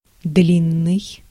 Ääntäminen
Tuntematon aksentti: IPA: /ˈdlʲinːɨj/